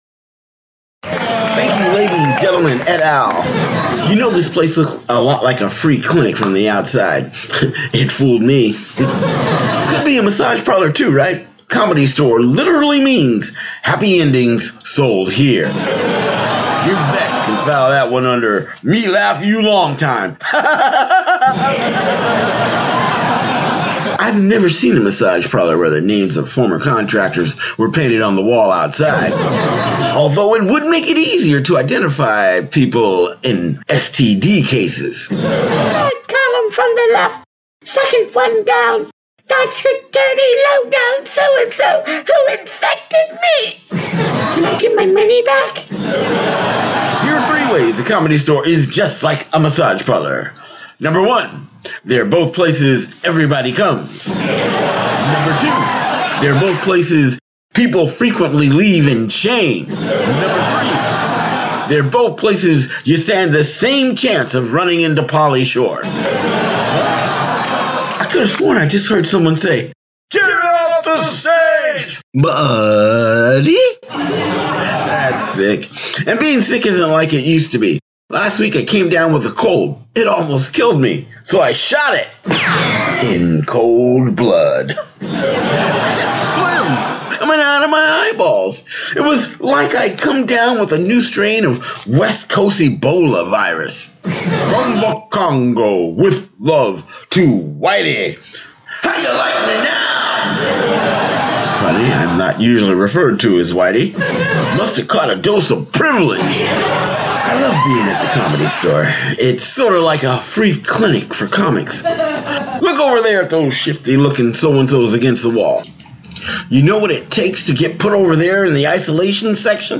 comedy album